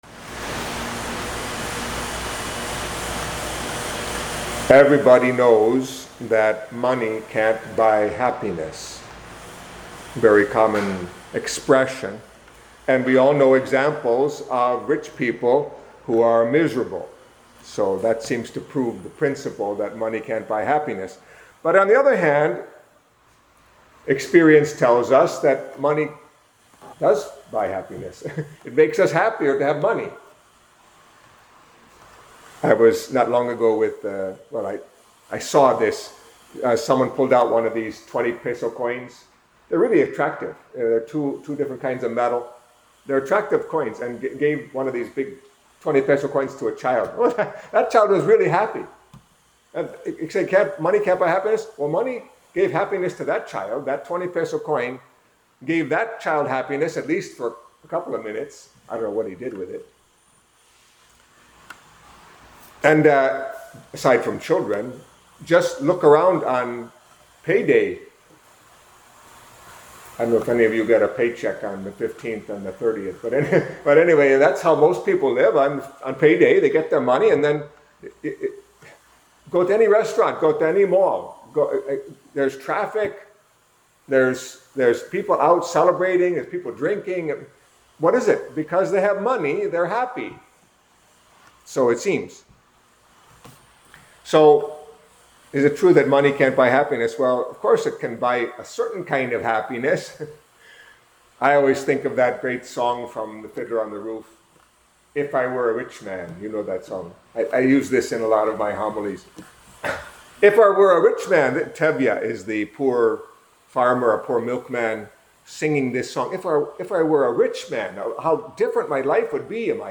Catholic Mass homily for the Eighteenth Sunday in Ordinary Time